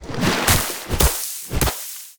Sfx_creature_pinnacarid_emerge_01.ogg